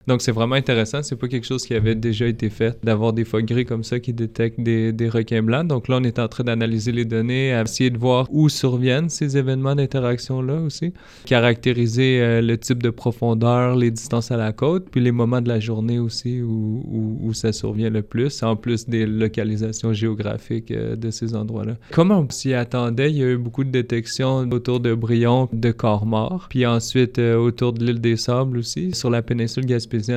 en visite aux studios de CFIM